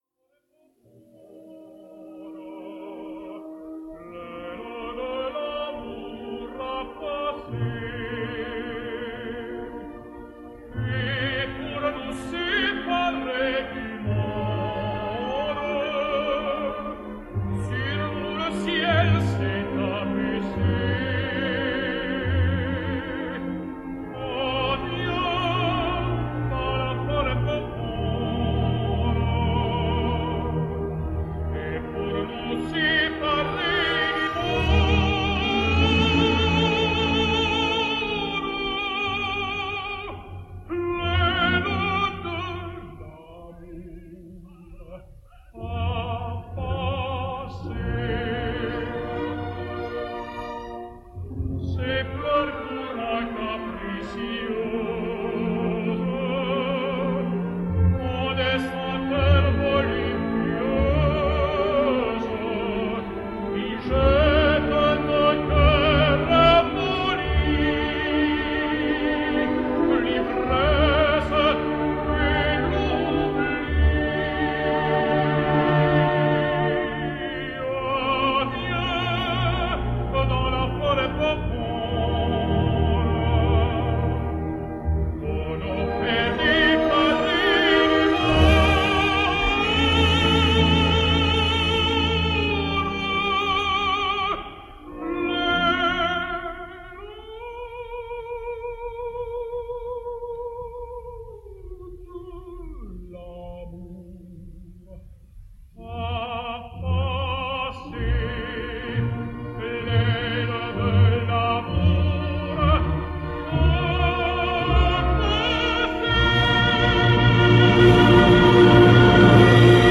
This internationally renowned tenor has a light pleasant voice.
Altogether, a thoughtful, elegant, and expressive singer.
Ah, Viens / Lakme / 1969 – Alain Vanzo